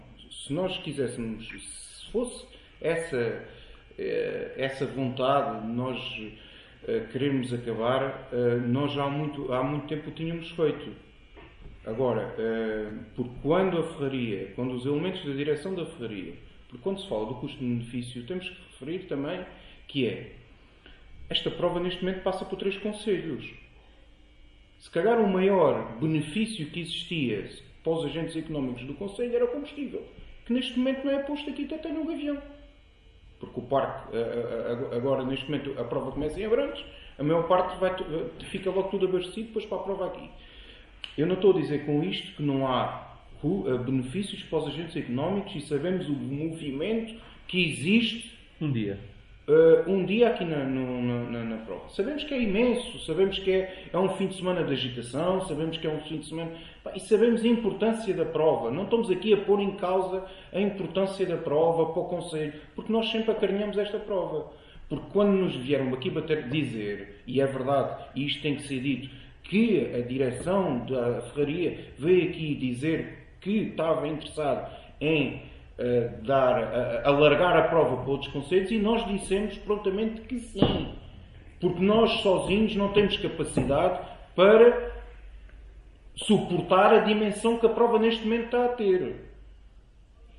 O Raid foi tema em discussão na última reunião de Câmara Municipal de Gavião.
ÁUDIO | VICE-PRESIDENTE CM GAVIÃO, ANTÓNIO SEVERINO: